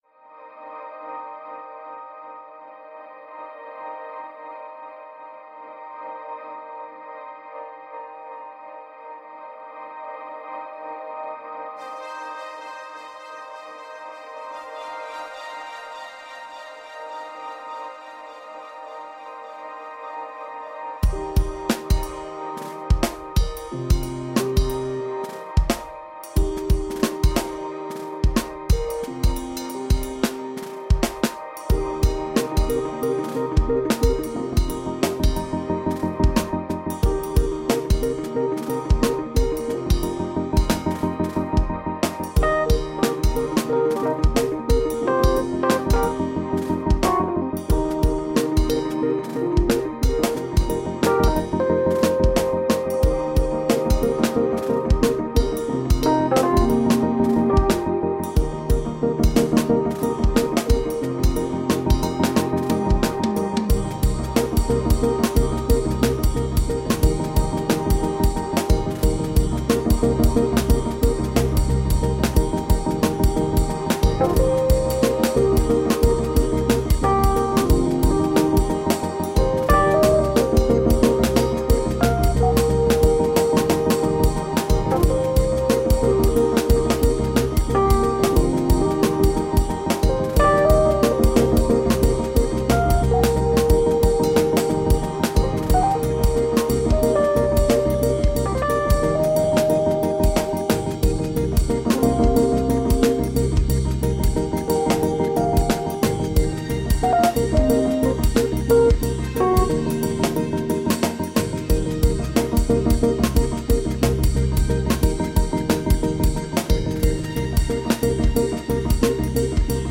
Lush downtempo electroacoustic grooves.